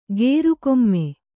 ♪ gēru komme